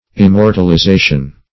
immortalization - definition of immortalization - synonyms, pronunciation, spelling from Free Dictionary
Search Result for " immortalization" : The Collaborative International Dictionary of English v.0.48: Immortalization \Im*mor`tal*i*za"tion\, n. The act of immortalizing, or state of being immortalized.
immortalization.mp3